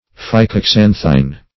phycoxanthine.mp3